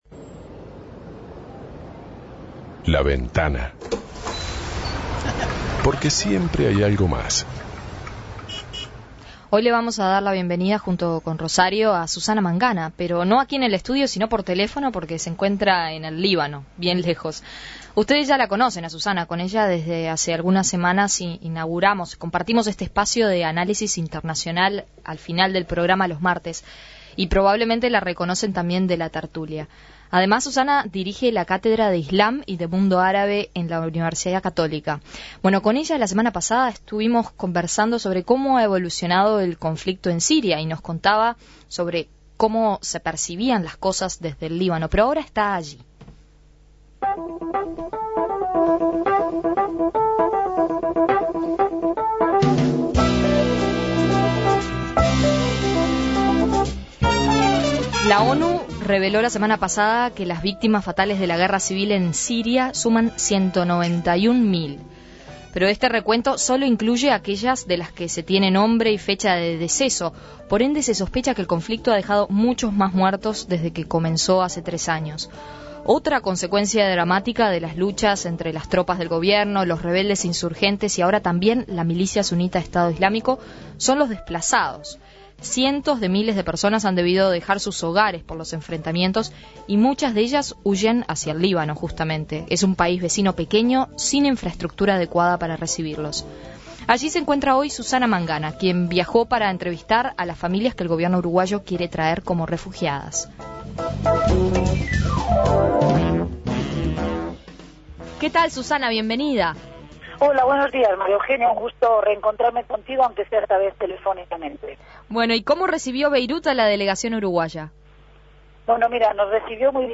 conversación